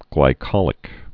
(glī-kŏlĭk)